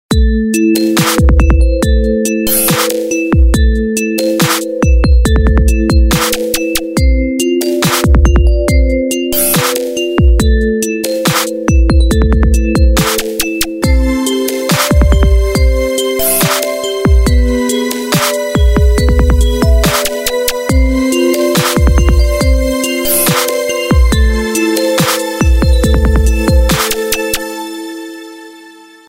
• Качество: 320, Stereo
спокойные
без слов
колокольчики
Классическая инструментальная музыка в Трап обработке